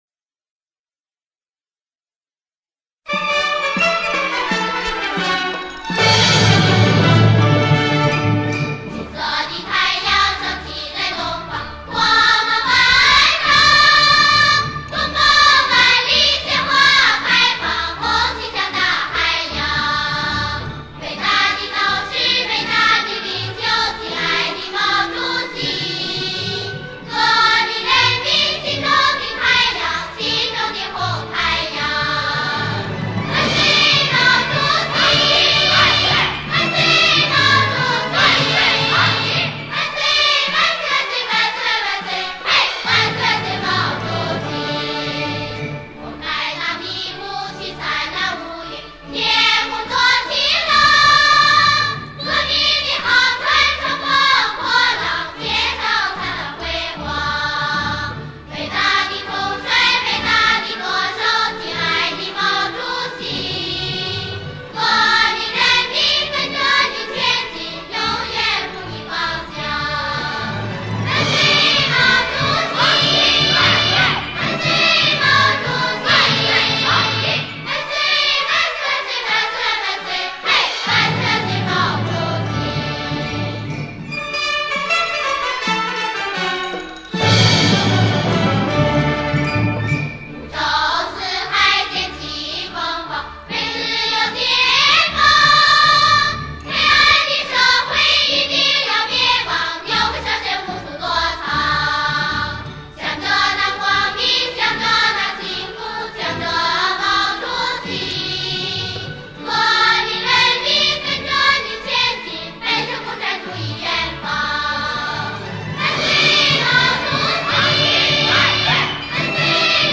童声齐唱 72版 80K MP3Pro